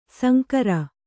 ♪ sankara